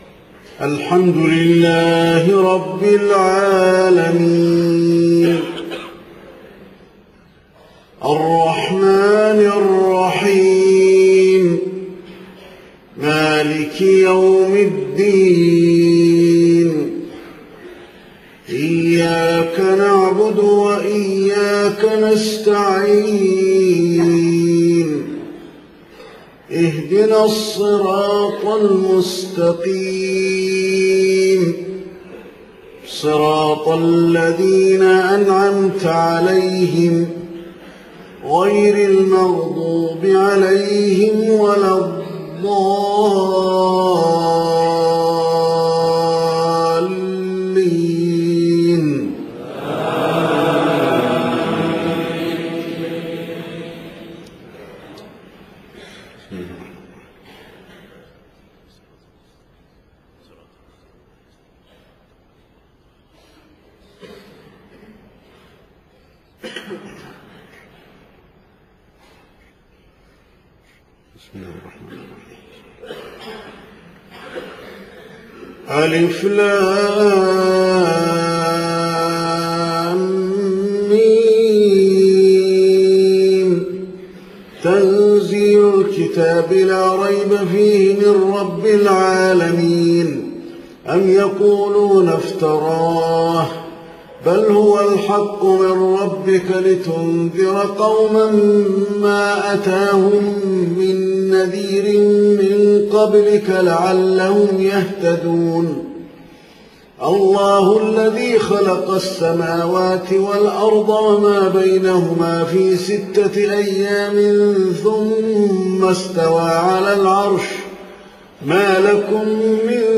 صلاة الفجر 5 محرم 1430هـ سورتي السجدة و الانسان > 1430 🕌 > الفروض - تلاوات الحرمين